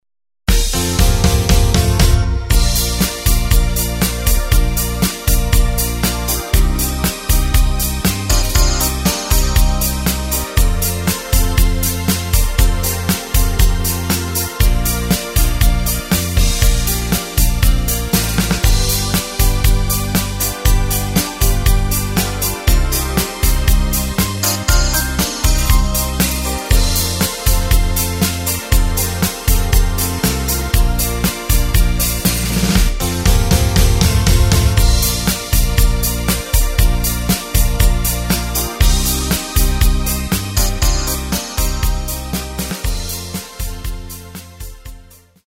Takt:          4/4
Tempo:         119.00
Tonart:            Bb
Playback mp3 Demo